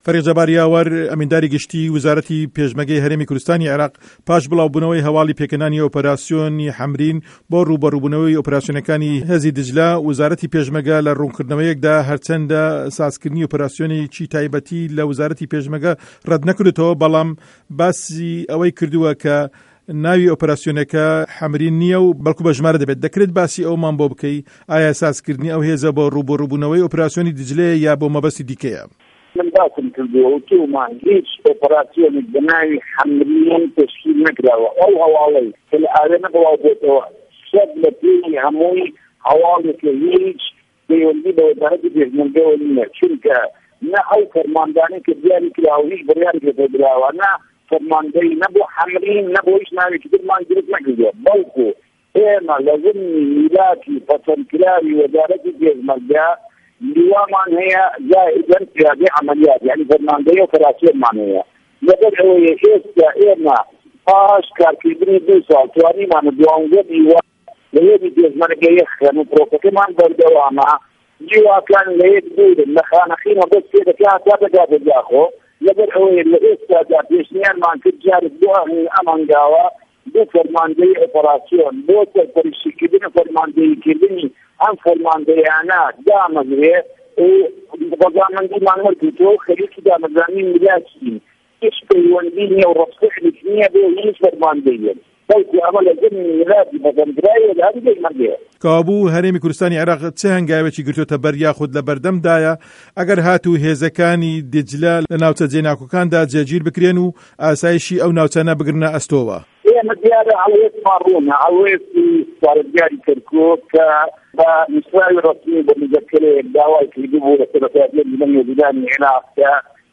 سه‌باره‌ت به‌و هه‌واڵه‌ و نیگه‌رانی خه‌ڵکی له‌ ناوچه جێ‌ ناکۆکه‌کان فه‌ریق جه‌بار یاوه‌ر ئەمینداری گشتی وەزارەتی پێشمەرگە له‌ وتووێژێکدا
وتووێژی جه‌بار یاوه‌ر